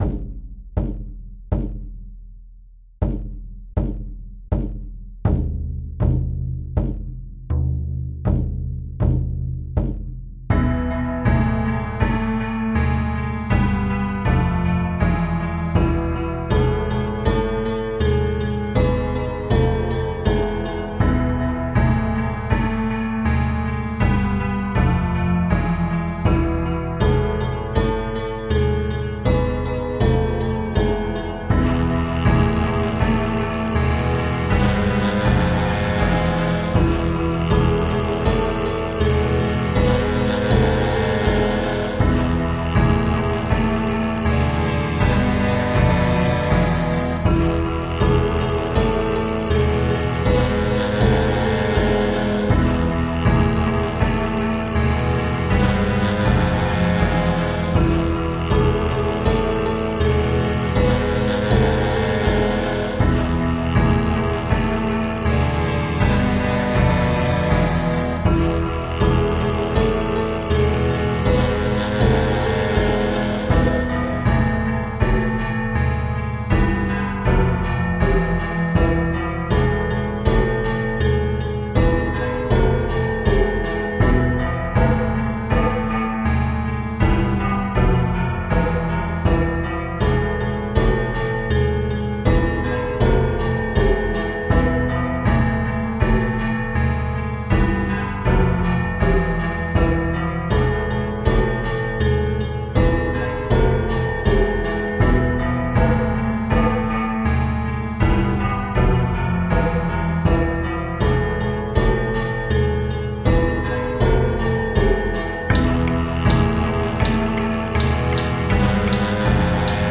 I don’t know if it was having to engage with ‘The Machine’ whilst programming, but in the last few days I’ve had a strange, heavy piece of music in my head. And, because of the power of free and open source software, and the Linux operating system, you can now listen to that – transcribed from my mind, with it’s 7/4-time delayed and reverberated strangeness, into reproducible sound.